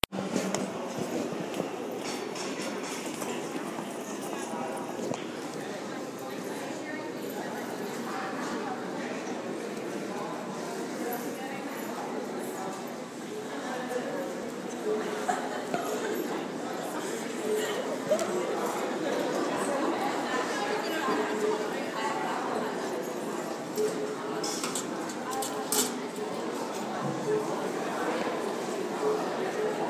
Field Recording
Student Center Cafeteria 4/6/16 4:50pm
chairs sliding across the floor, lots of people talking and laughing, people walking, cash register clink.